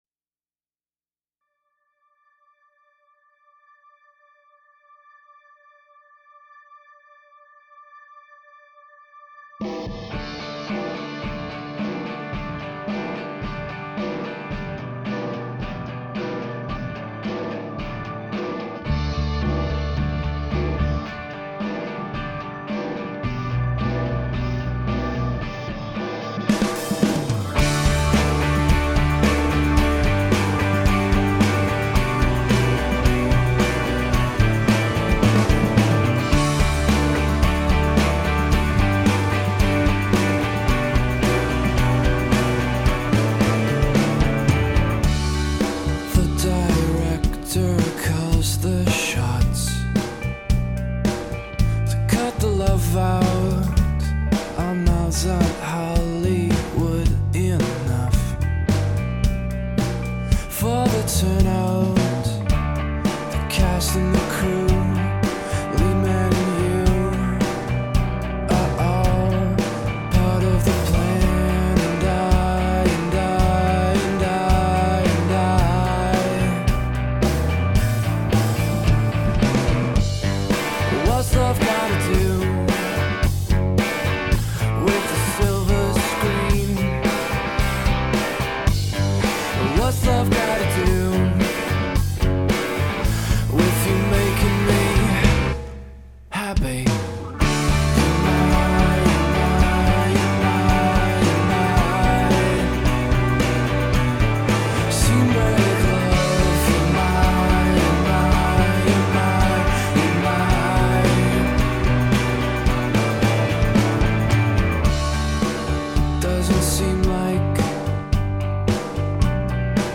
I find the song very soothing and almost hypnotic.